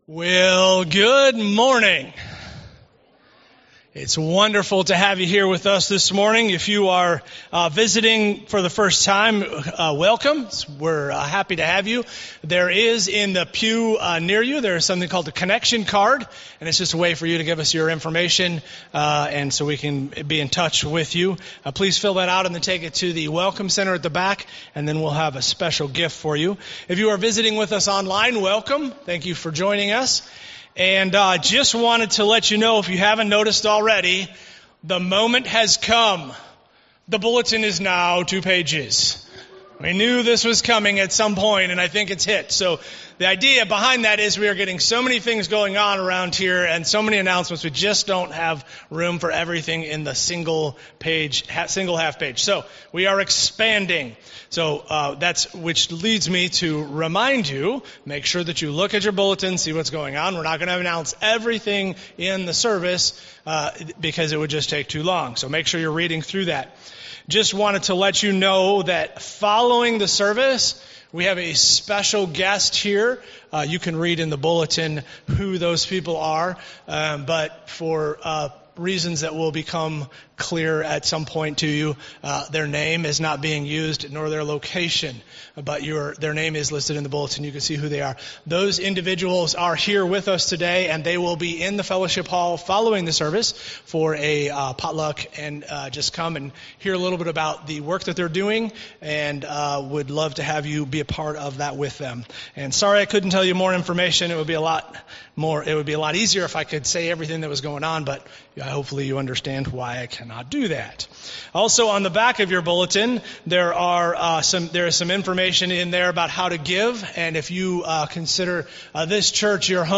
Sunday Morning Worship